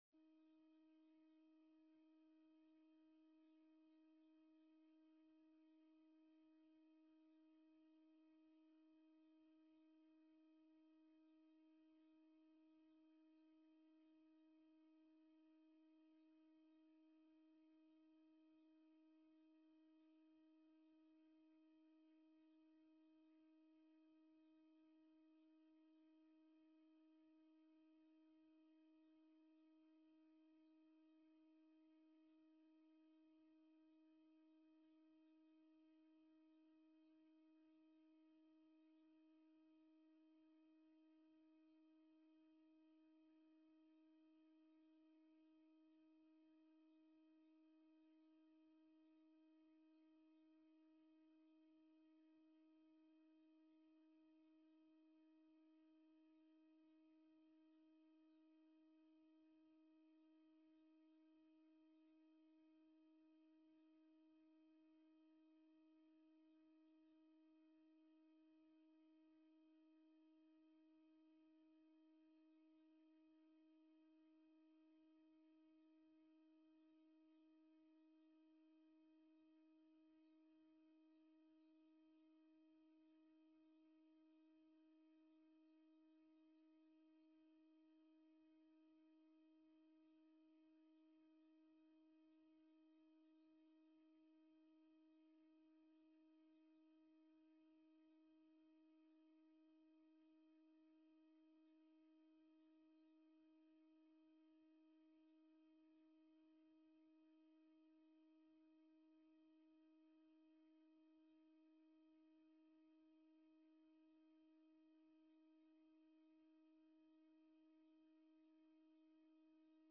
De gemeenteraad vergadert digitaal.